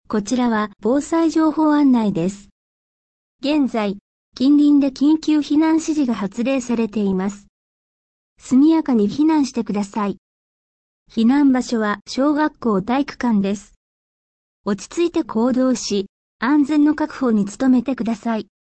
AI音声合成・音声読み上げ（WEB テキスト）ソフトのReadSpeaker（リードスピーカー）